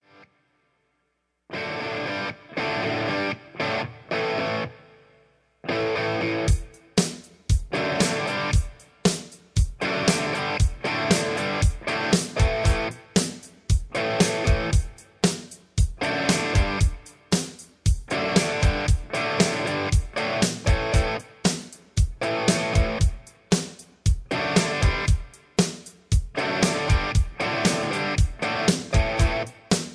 karaoke collection